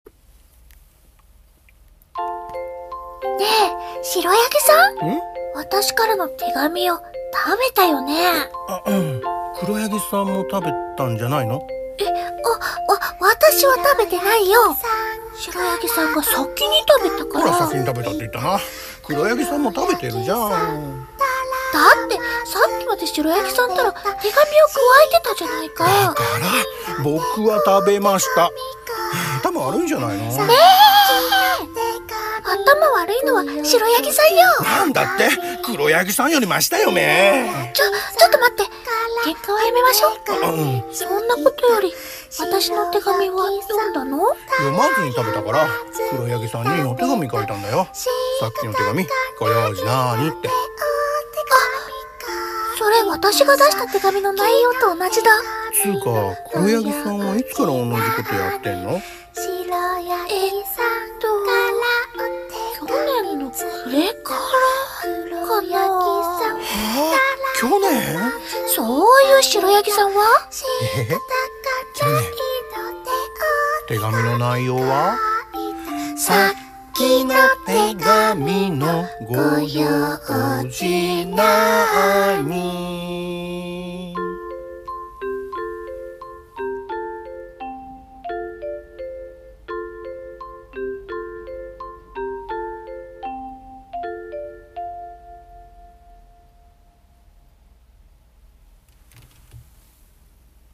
【二人用声劇台本】白ヤギさんと黒ヤギさん